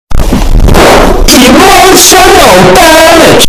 Emotional Damage Ultra Bass Boosted Soundboard: Play Instant Sound Effect Button